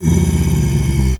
pgs/Assets/Audio/Animal_Impersonations/bear_roar_soft_12.wav at master
bear_roar_soft_12.wav